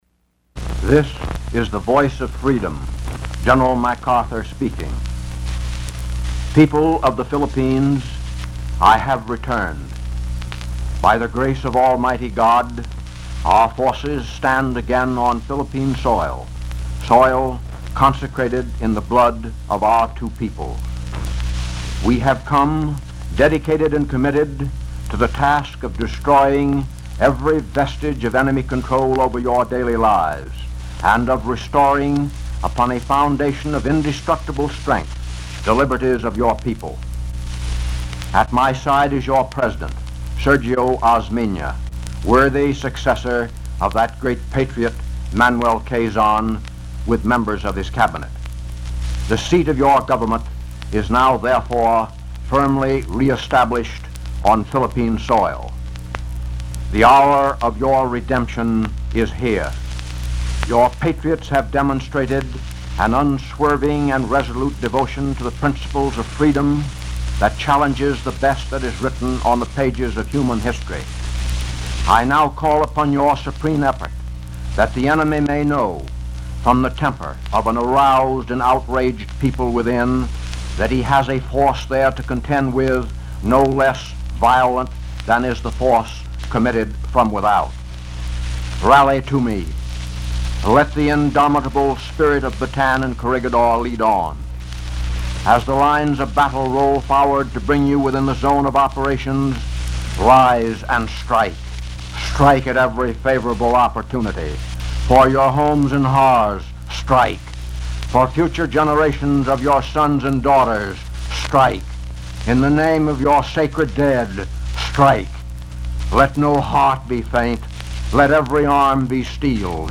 World War II speech, "I Have Returned"